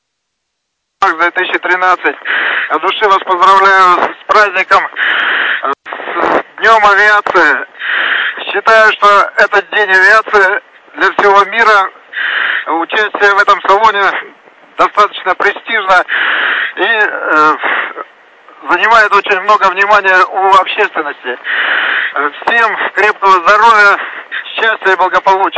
Начало » Записи » Записи радиопереговоров - авиация